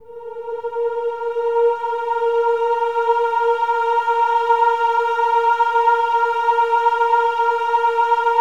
OH-AH  A#4-R.wav